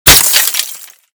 / gamedata / sounds / material / bullet / collide / glass02hl.ogg 20 KiB (Stored with Git LFS) Raw History
glass02hl.ogg